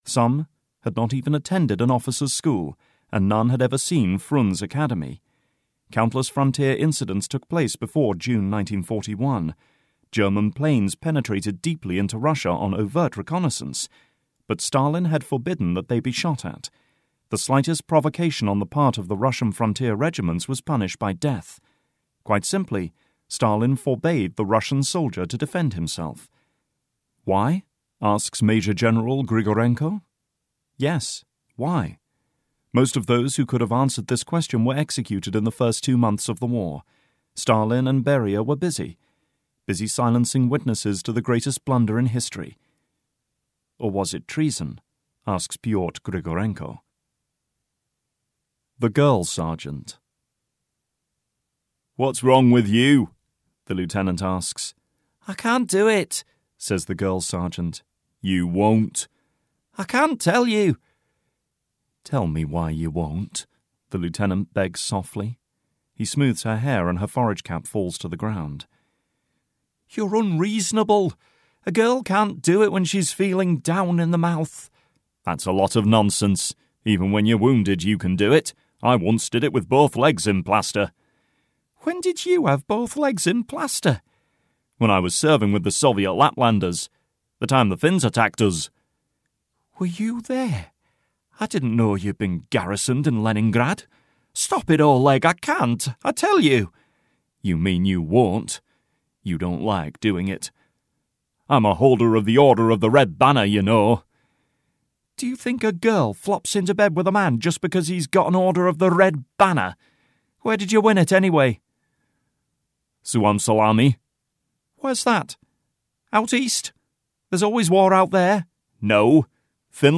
Blitzfreeze (EN) audiokniha
Ukázka z knihy